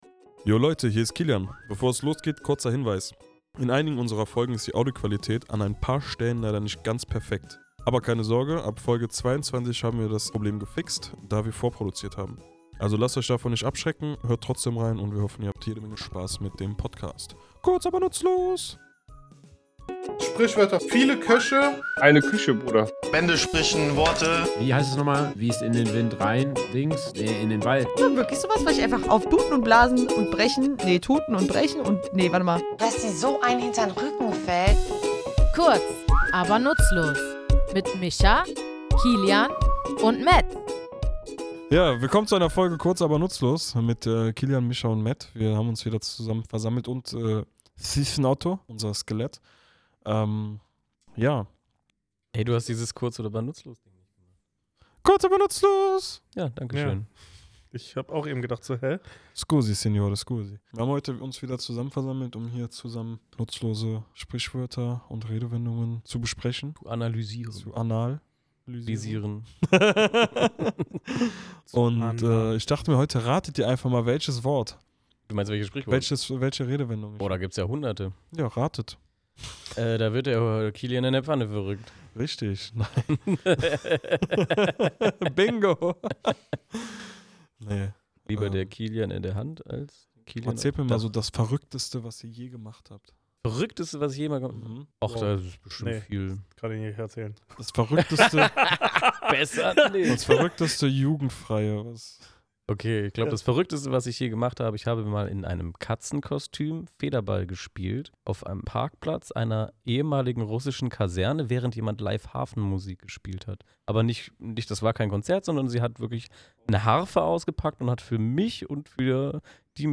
Was bedeutet es, wenn jemand völlig außer Kontrolle gerät, und woher stammt dieser Ausdruck? Wir, drei tätowierende Sprachenthusiasten, gehen in unserem Tattoostudio der Geschichte und Bedeutung dieser dynamischen Redensart auf den Grund.